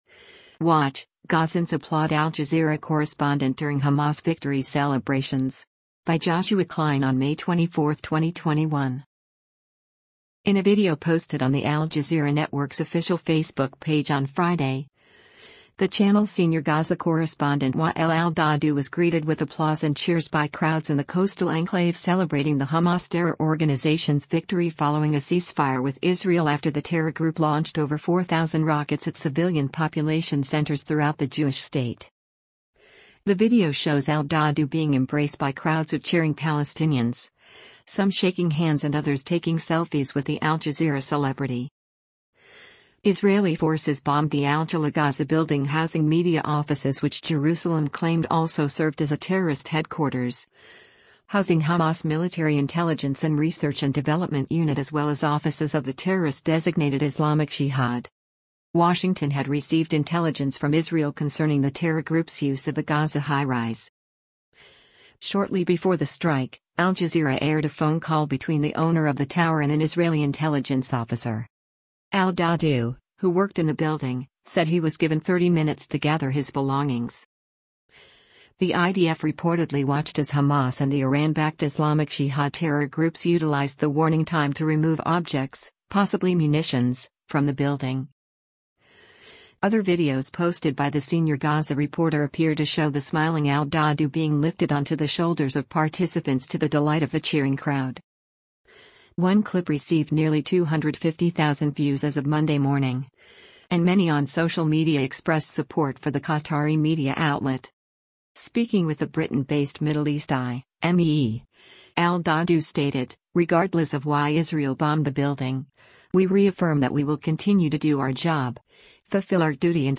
WATCH: Gazans Applaud Al-Jazeera Correspondent During Hamas ‘Victory’ Celebrations